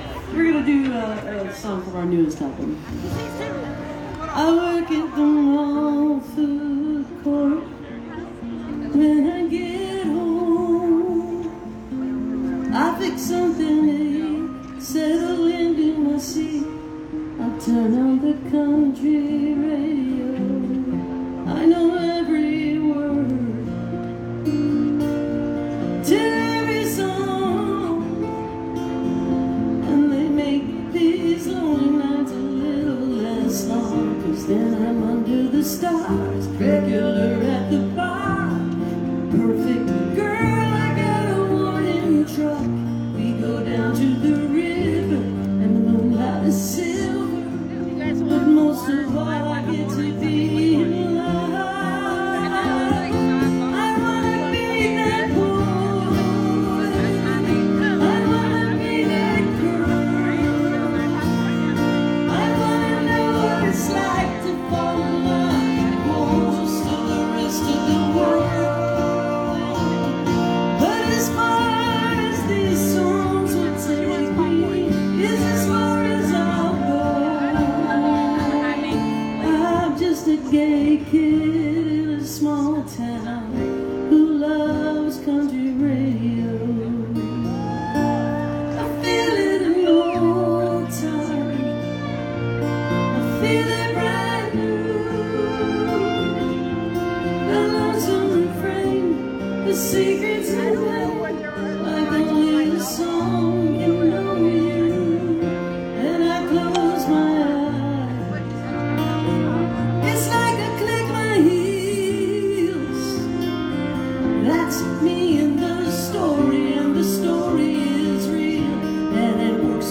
(captured from the facebook livestream)